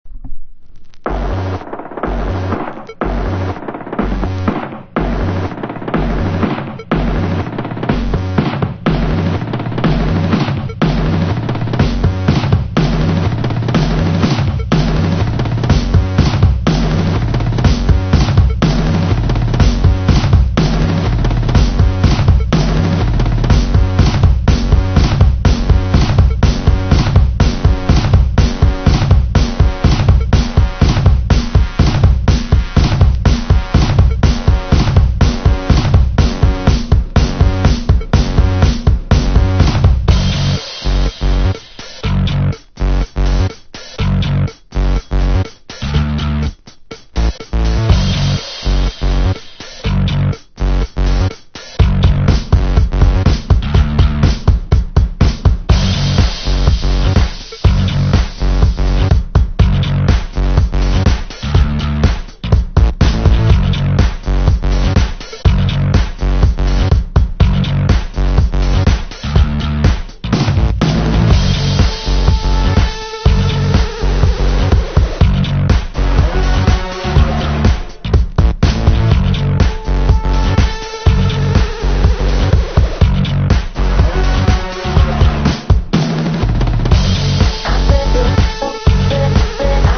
ELECTRO HOUSE / TECH HOUSE
SOCIETY DUB MIX